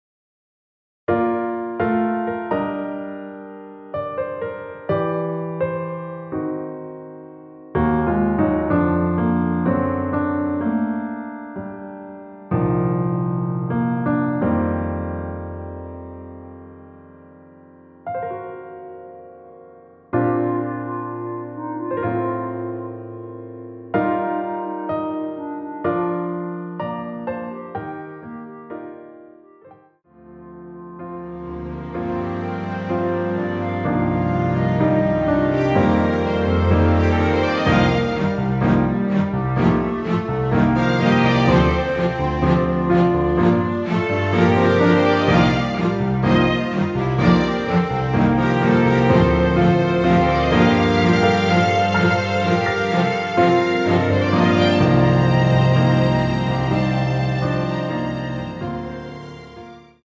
원키에서(-3)내린 멜로디 포함된 MR입니다.(미리듣기 확인)
Bb
멜로디 MR이라고 합니다.
앞부분30초, 뒷부분30초씩 편집해서 올려 드리고 있습니다.
중간에 음이 끈어지고 다시 나오는 이유는